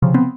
warning.mp3